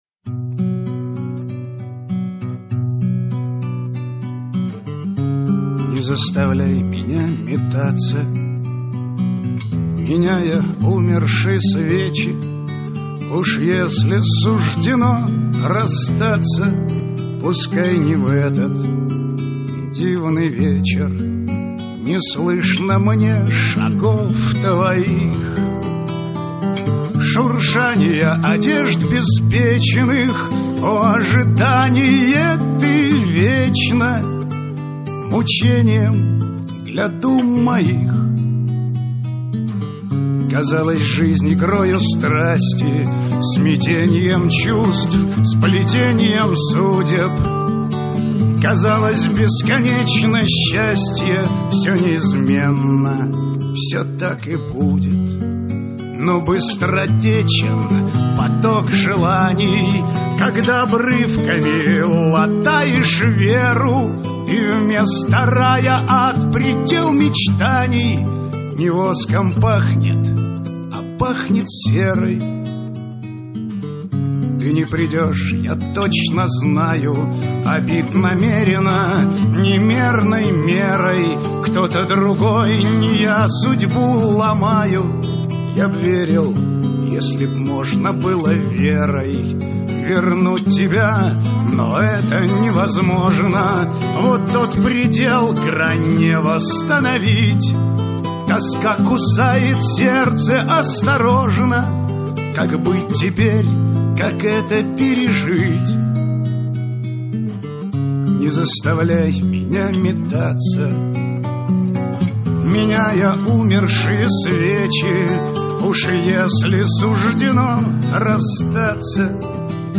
Романс
• Жанр: Авторская песня